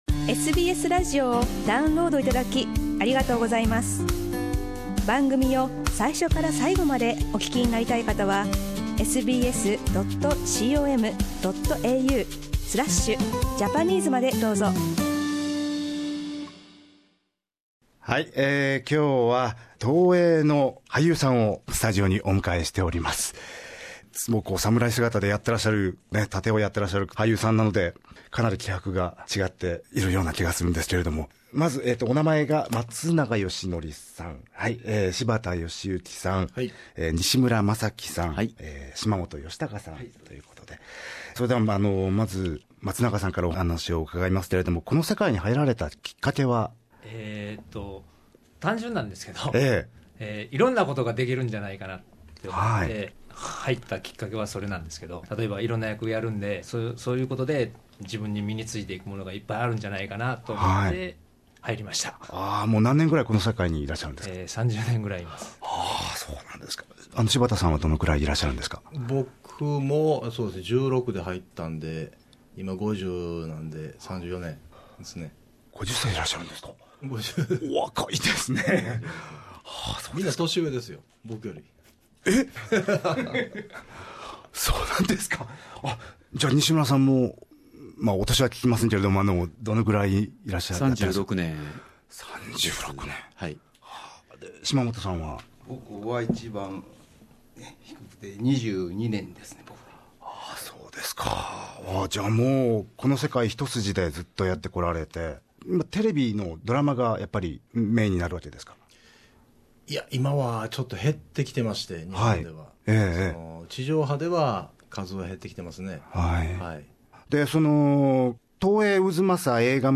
interviewed four actors from Toei Film Studio in Kyoto